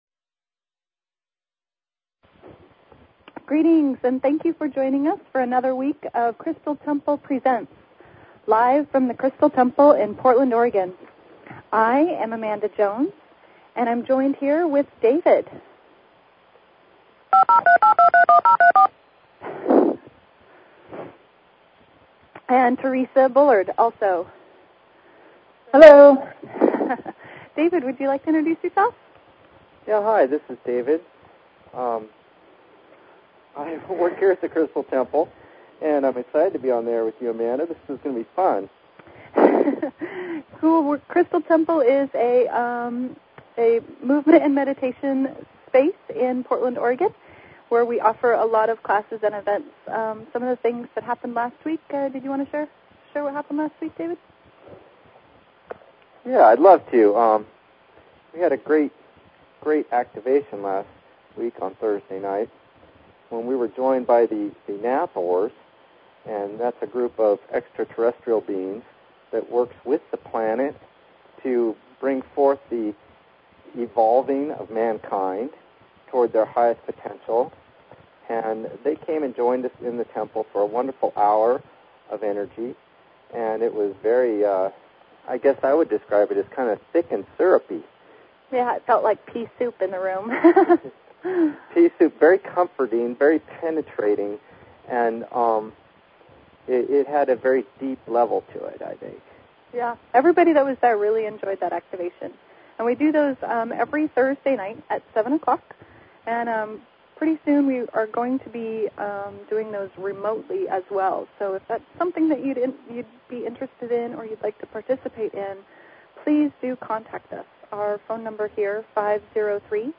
Talk Show Episode, Audio Podcast, Crystal_Temple_Presents and Courtesy of BBS Radio on , show guests , about , categorized as